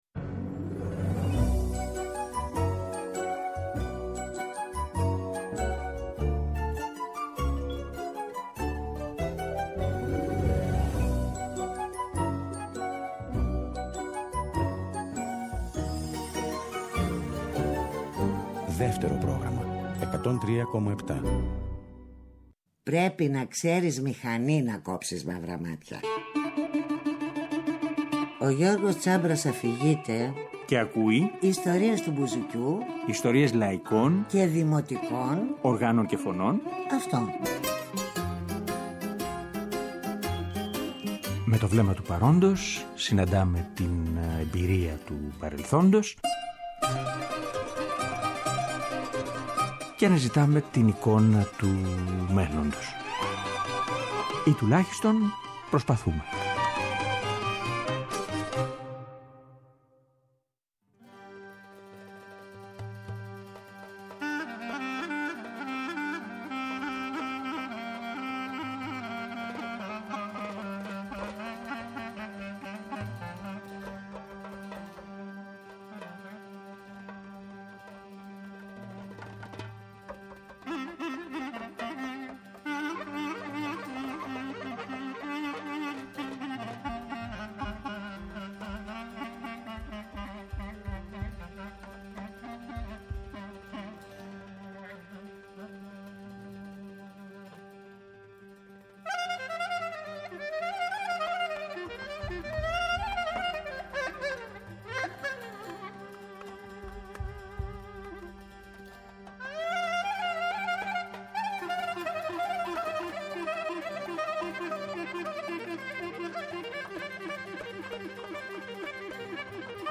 Ο Μίκης εξιστορεί και ιστορεί...